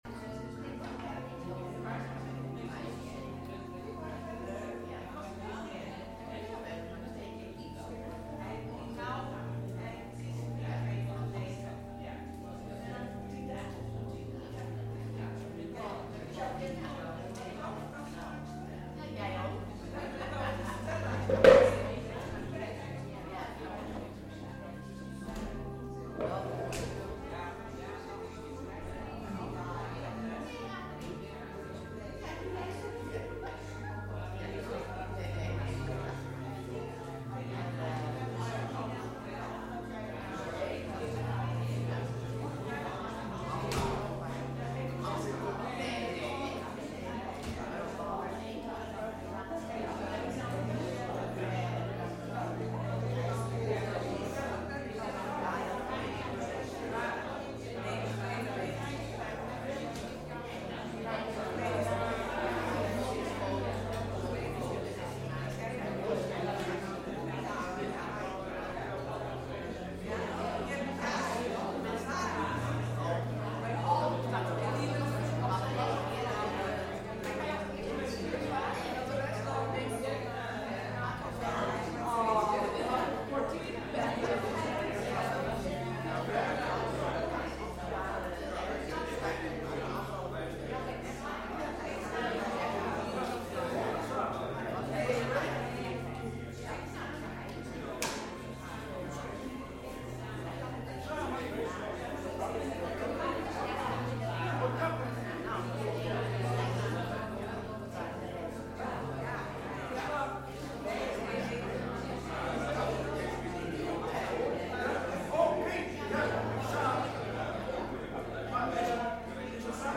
Diensten beluisteren
Tijdens de samenkomsten is er veel aandacht voor muziek, maar ook voor het lezen van Gods woord en het overdenken hiervan. We zingen voornamelijk uit Opwekking en de Johannes de Heer bundel.